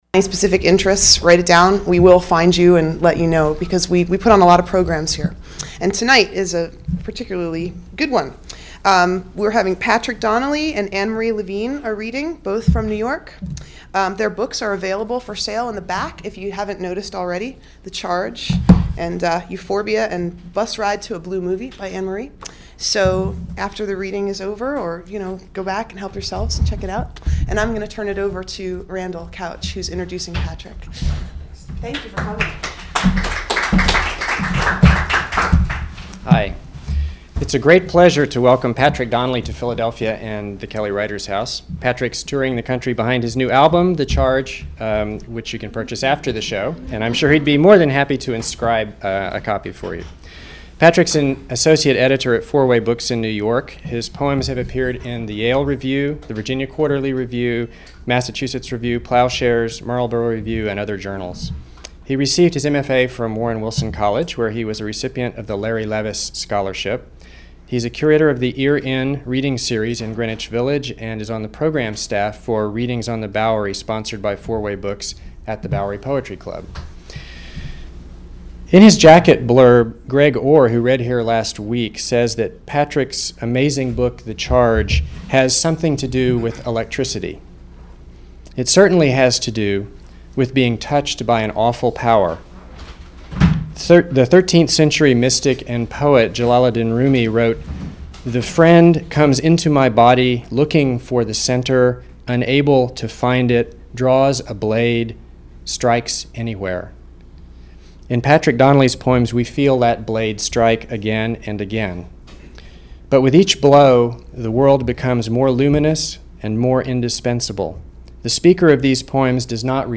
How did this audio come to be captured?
Reading at Kelly Writers House, 2/10/04